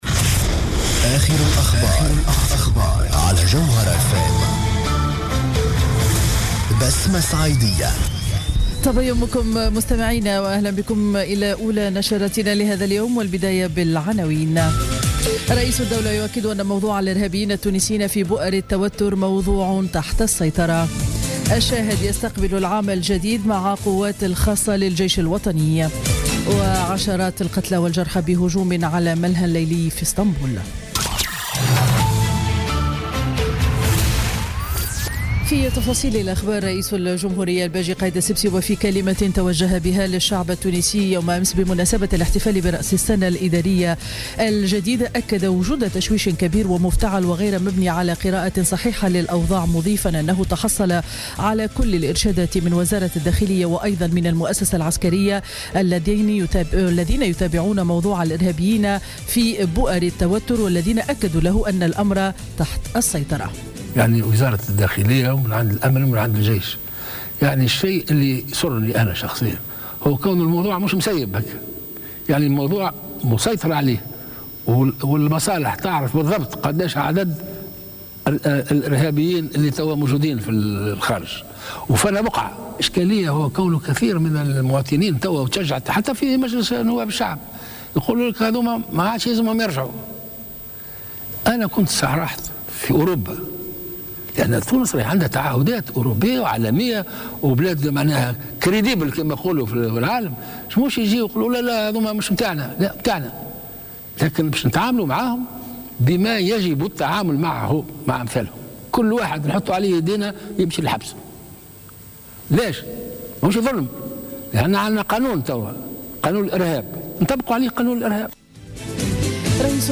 نشرة أخبار السابعة صباحا ليوم الأحد غرة جانفي 2017